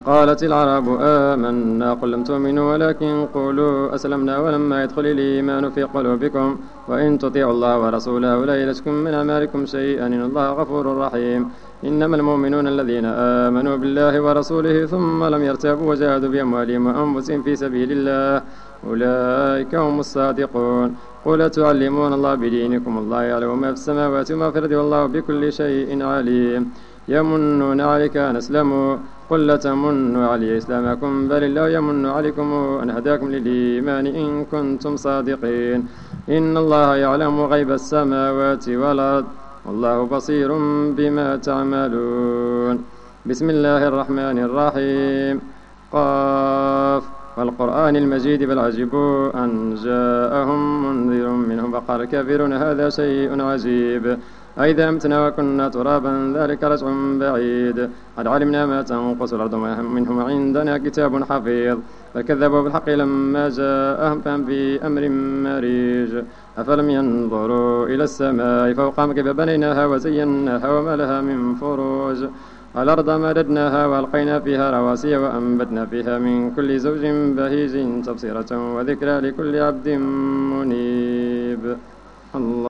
صلاة التراويح ليوم 25 رمضان 1431 بمسجد ابي بكر الصديق ف الزو
صلاة رقم 06 ليوم 25 رمضان 1431 الموافق سبتمبر 2010